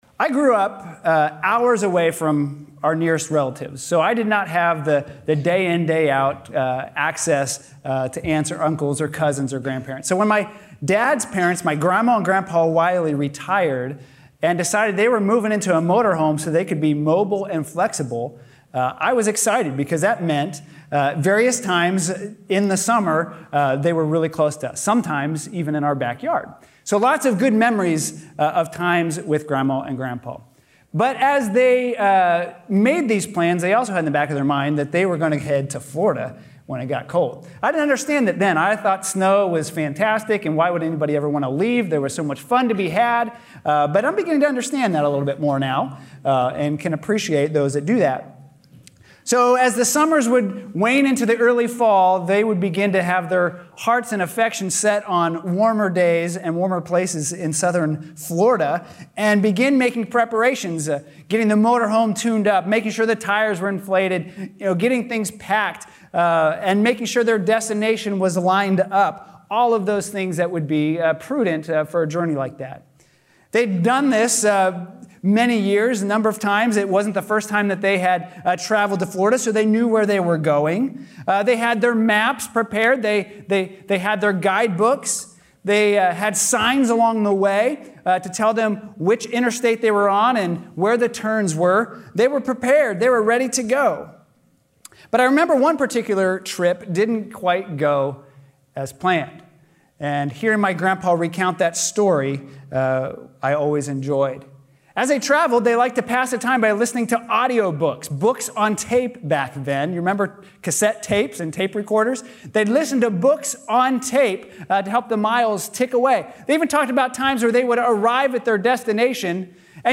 A sermon from the series "Transparent."